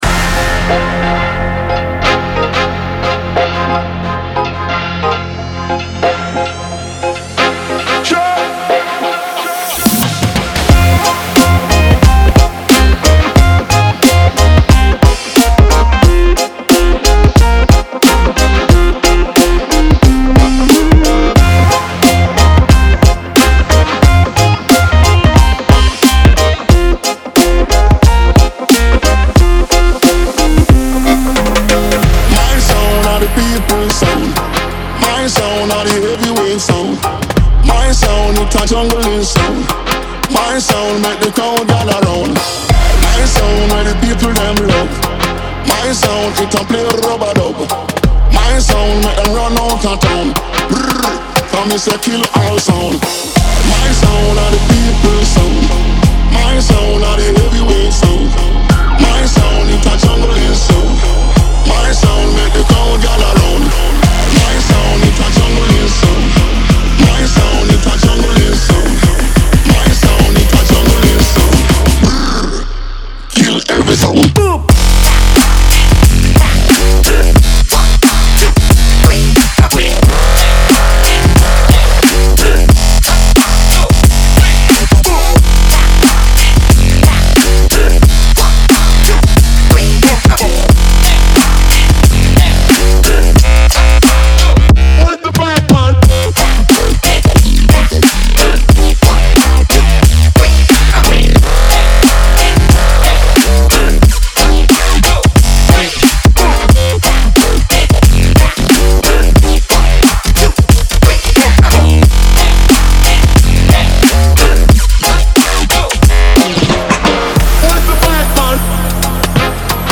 BPM90-90
Audio QualityPerfect (High Quality)
Dubstep song for StepMania, ITGmania, Project Outfox
Full Length Song (not arcade length cut)